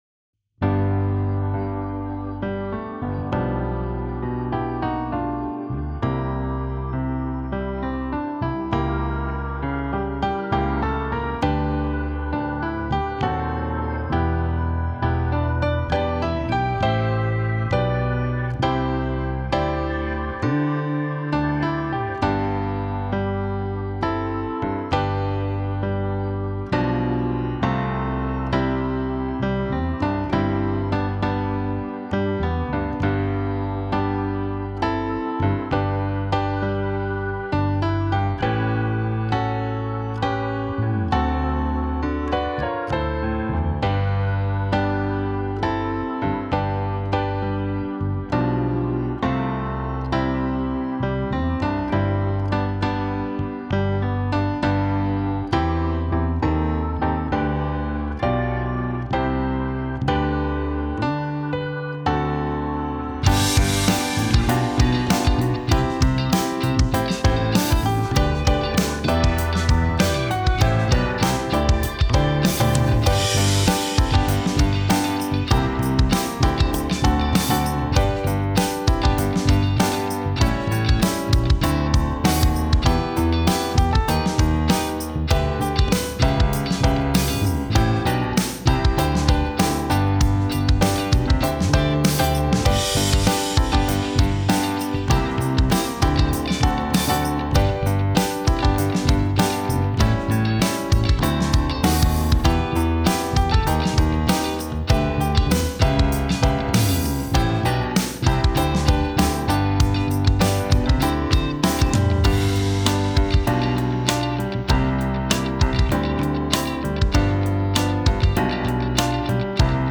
Key: G BPM: 98 Time sig: 4/4 Duration:  Size: 9.1MB
Choral Gospel Worship